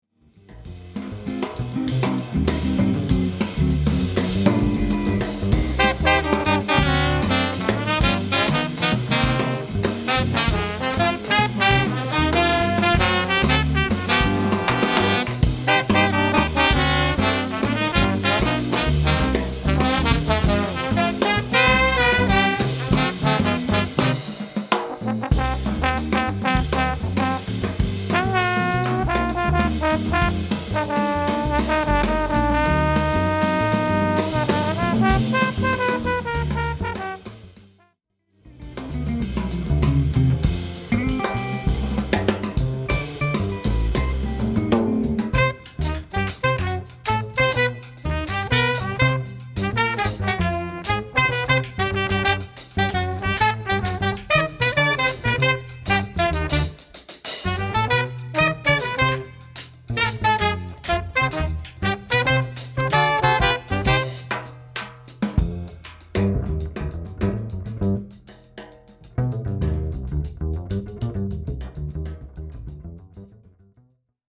Voicing: Combo Sextet